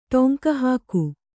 ♪ ṭoŋka hāku